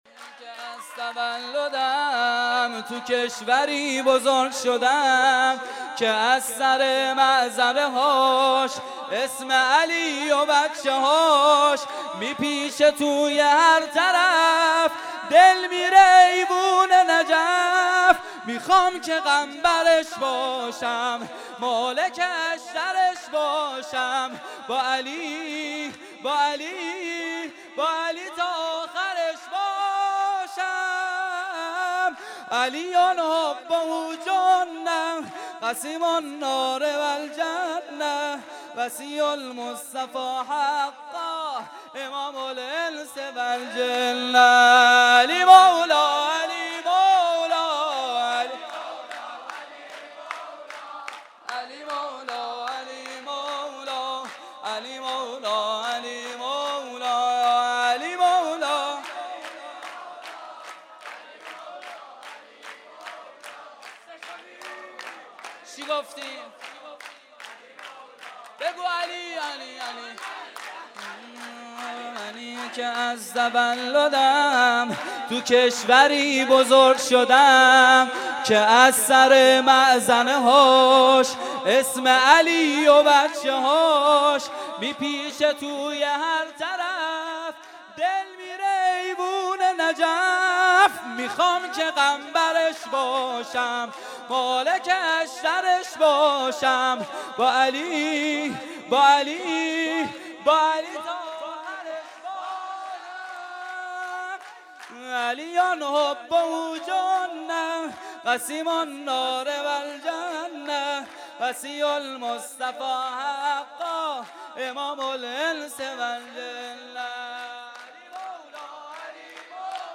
خیمه گاه - هیئت بچه های فاطمه (س) - سرود | منی که از تولدم تو کشوری بزرگ شدم
مراسم جشن ولادت حضرت فاطمه الزهرا (س)